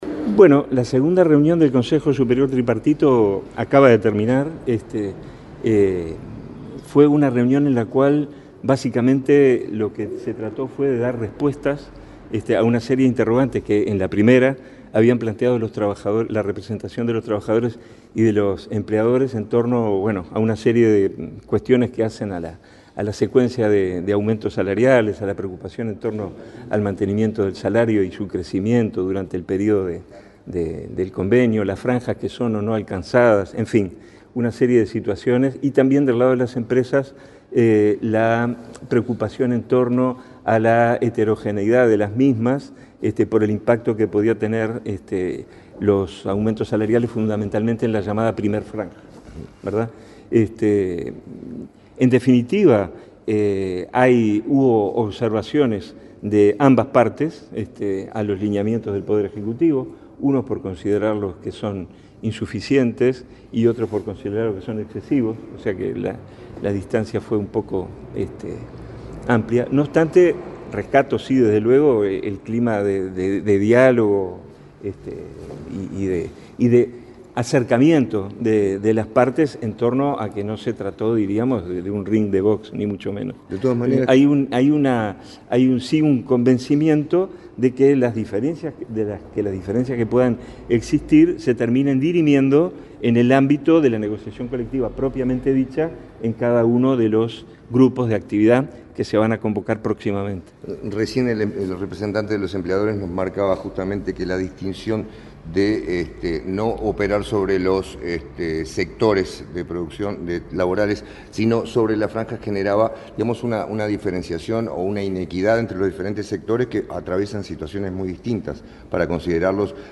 Declaraciones del subsecretario de Trabajo y Seguridad Social, Hugo Barretto
Declaraciones del subsecretario de Trabajo y Seguridad Social, Hugo Barretto 09/07/2025 Compartir Facebook X Copiar enlace WhatsApp LinkedIn Tras la segunda reunión del Consejo Superior Tripartito, en una nueva ronda del Consejo de Salarios, el subsecretario de Trabajo y Seguridad Social, Hugo Barretto, brindó declaraciones a la prensa.